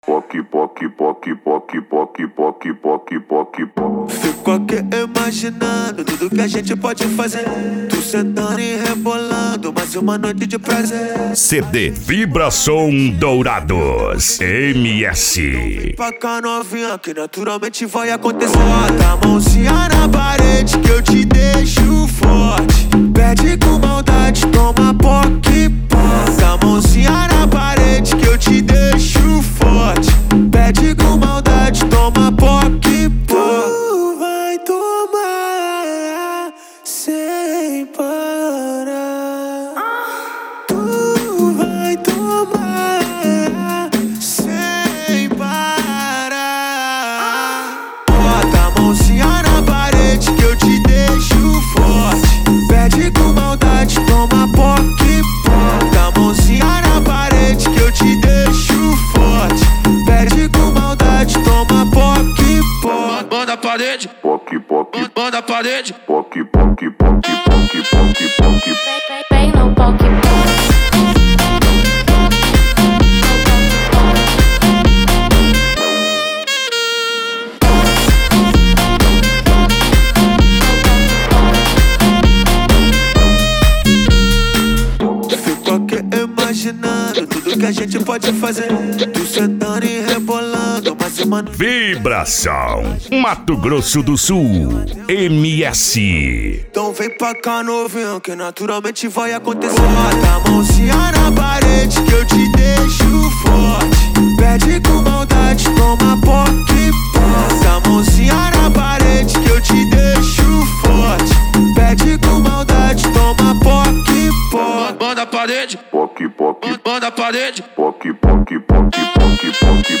Bass
Eletronica
PANCADÃO